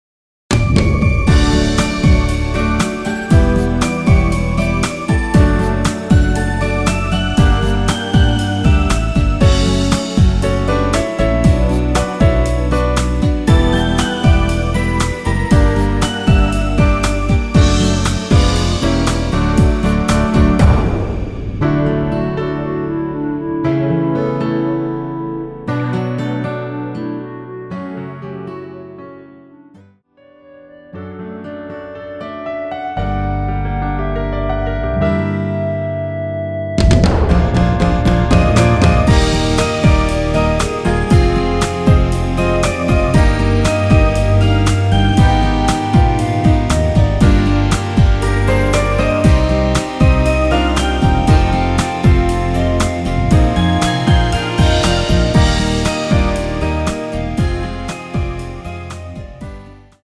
(-1) 내린 멜로디포힘된 MR 입니다.
◈ 곡명 옆 (-1)은 반음 내림, (+1)은 반음 올림 입니다.
멜로디 MR이란
앞부분30초, 뒷부분30초씩 편집해서 올려 드리고 있습니다.
중간에 음이 끈어지고 다시 나오는 이유는